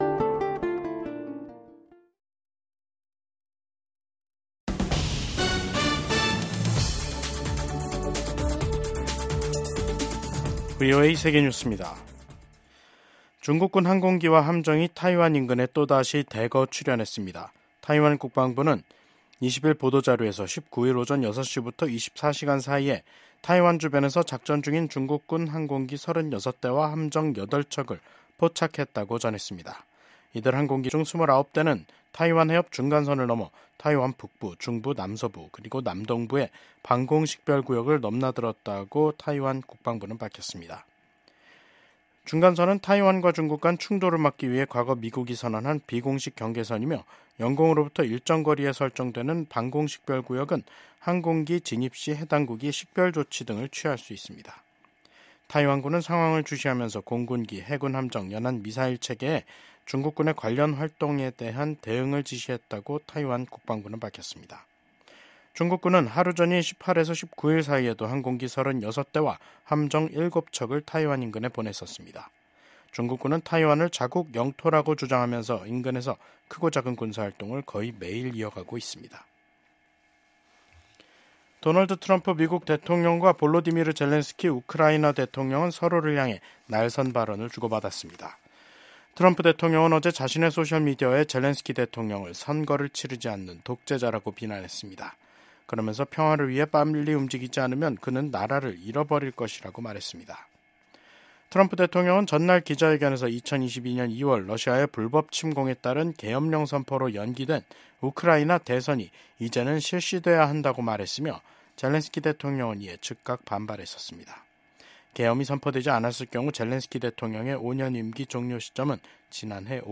VOA 한국어 간판 뉴스 프로그램 '뉴스 투데이', 2025년 2월 20일 2부 방송입니다. 미국 정부는 중국이 타이완 해협의 평화와 안정을 해치고 있다는 점을 지적하며 일방적 현상 변경에 반대한다는 입장을 확인했습니다. 미국의 ‘핵무기 3축’은 미국 본토에 대한 북한의 대륙간탄도미사일 공격을 효과적으로 억지할 수 있다고 미국 공군 소장이 말했습니다.